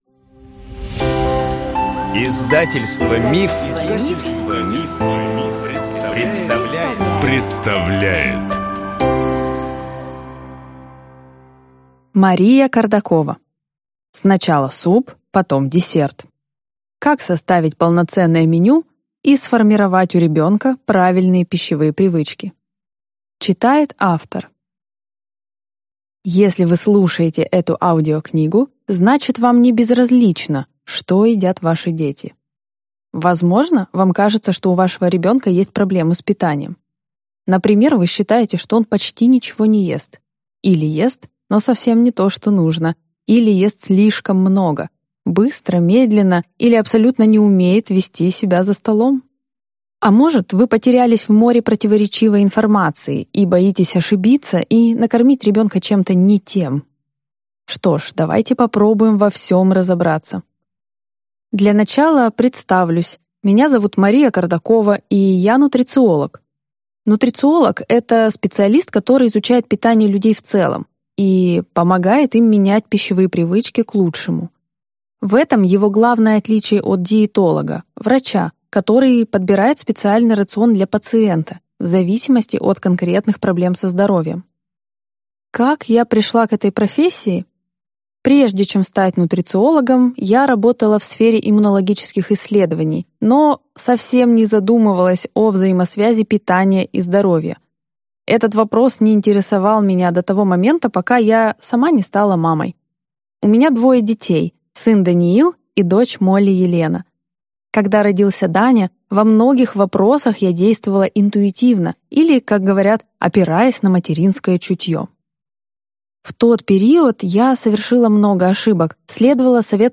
Аудиокнига Сначала суп, потом десерт | Библиотека аудиокниг